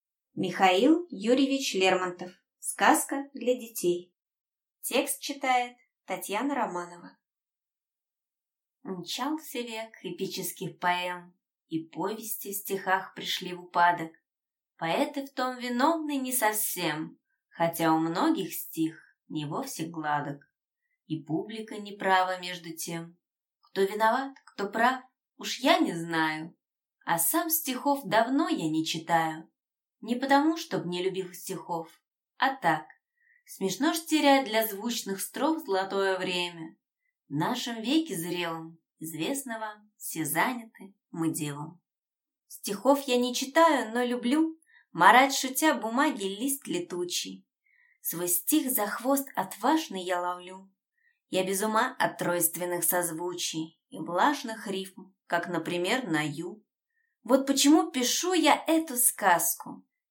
Аудиокнига Сказка для детей | Библиотека аудиокниг
Прослушать и бесплатно скачать фрагмент аудиокниги